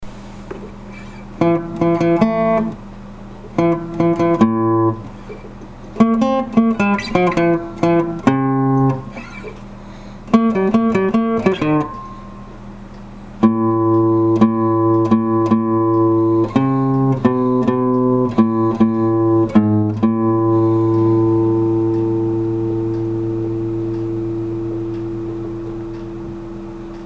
Записанный в домашних условиях на одном дыхании